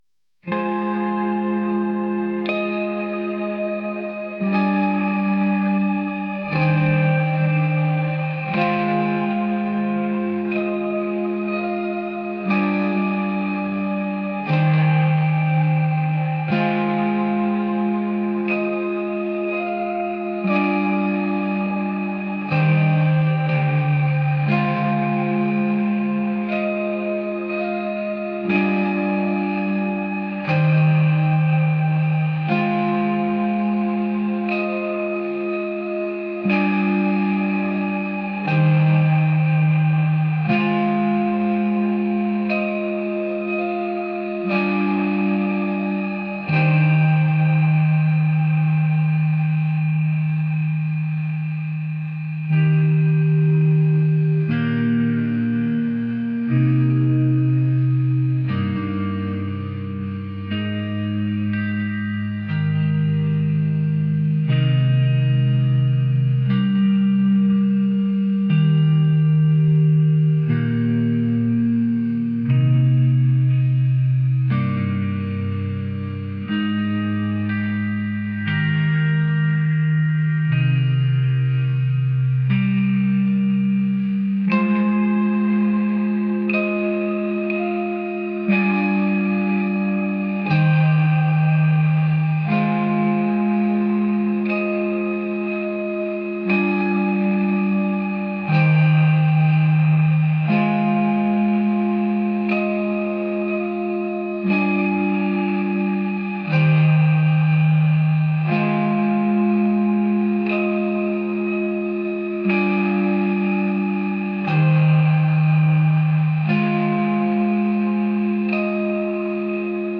atmospheric | alternative | indie